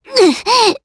Pansirone-Vox_Damage_jp_01.wav